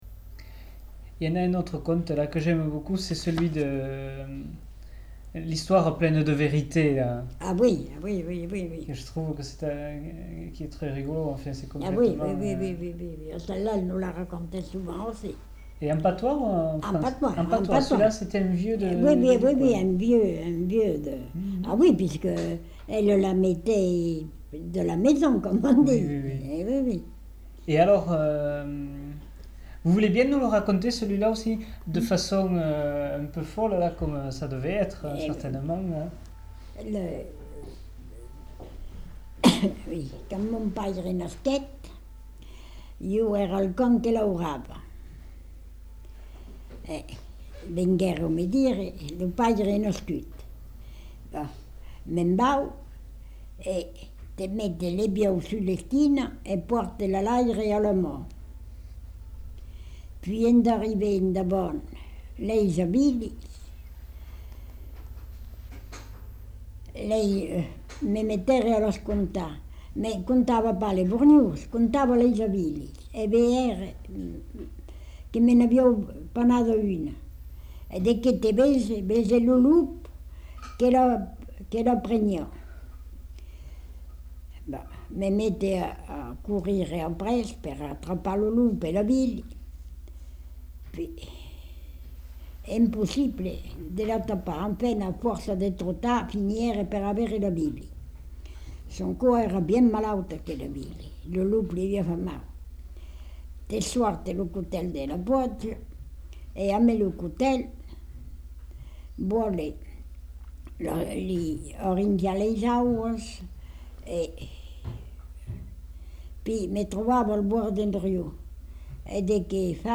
Lieu : Tournay
Genre : conte-légende-récit
Effectif : 1
Type de voix : voix de femme
Production du son : parlé